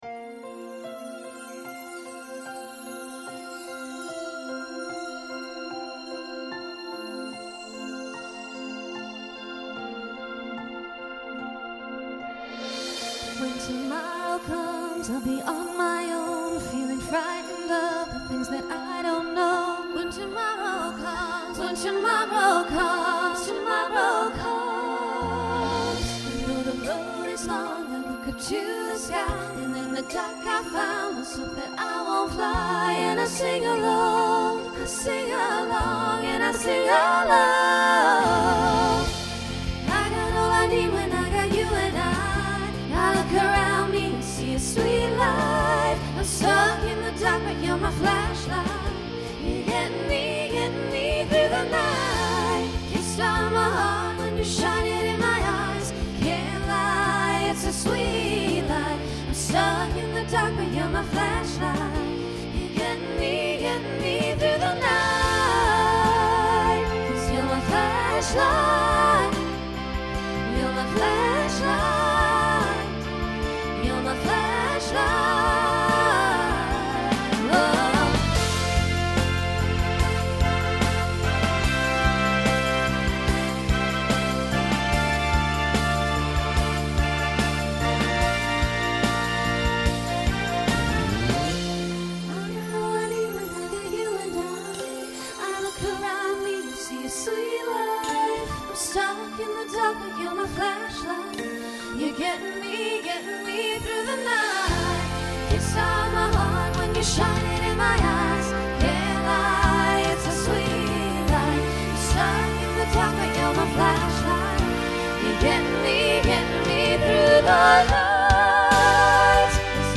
SSA/TTB/SATB
Voicing Mixed Instrumental combo Genre Pop/Dance